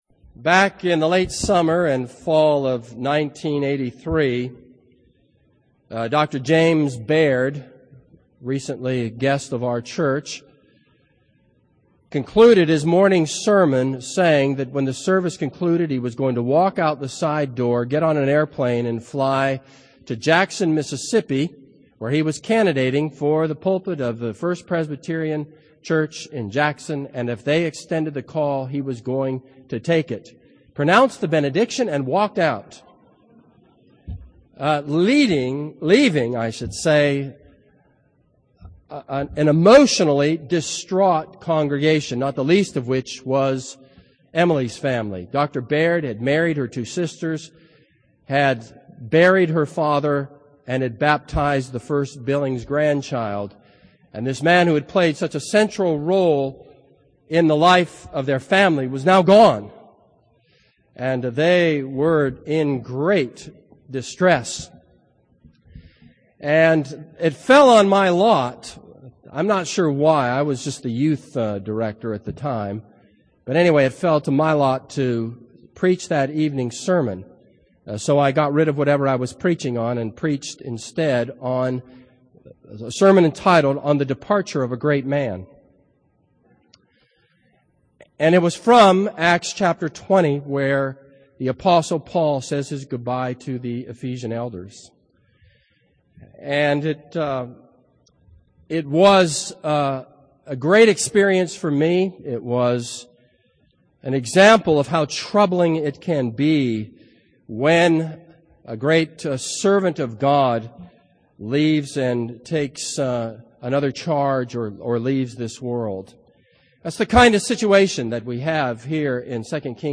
This is a sermon on 2 Kings 2.